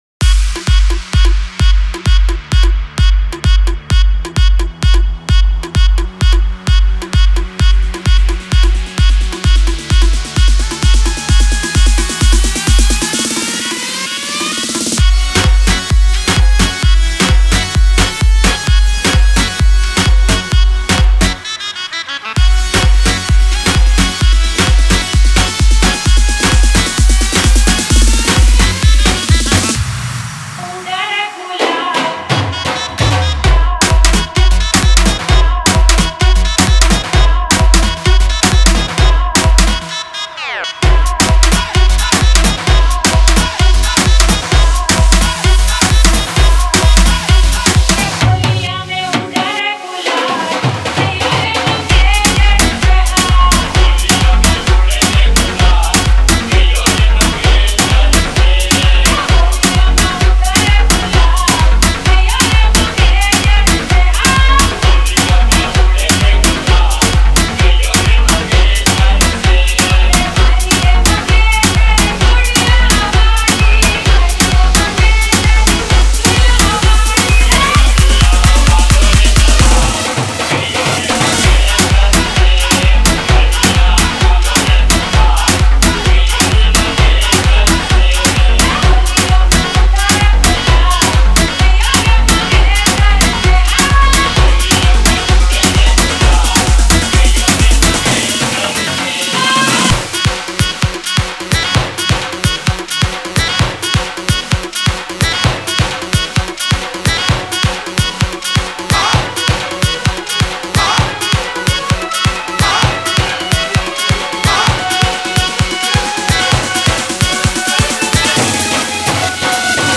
HOLI SPECIAL DJ SONG